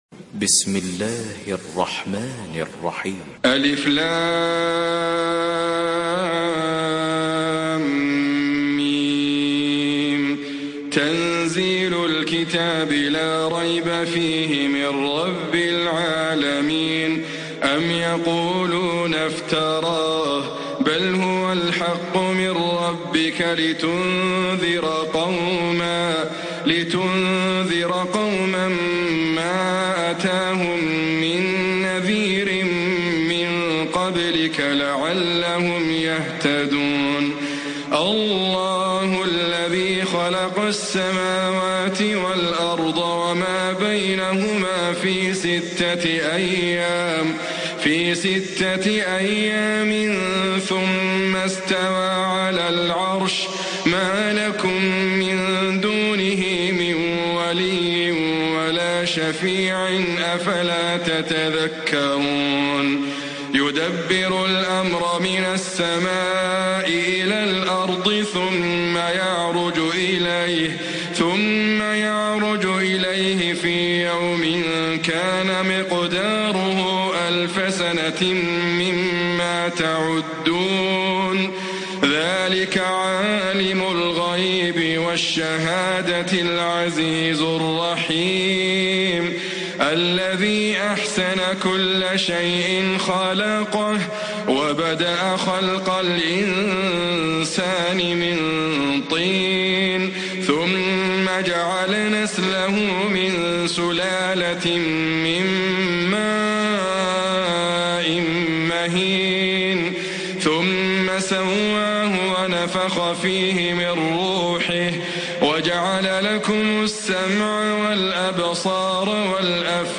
Sourate As-Sajdah MP3 à la voix de Idriss Abkar par la narration Hafs
Une récitation touchante et belle des versets coraniques par la narration Hafs An Asim.
Murattal Hafs An Asim